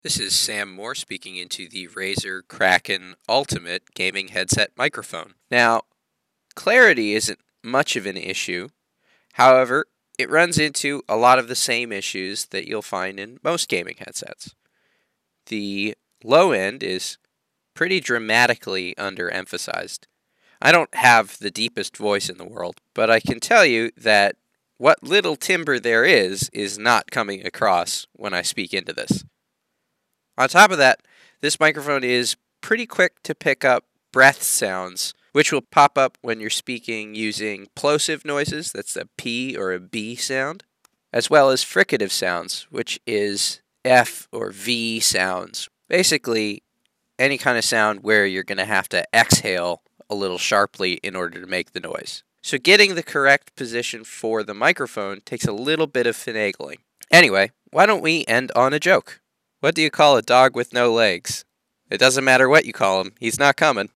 Razer-Kraken-Ultimate-mic-sample.mp3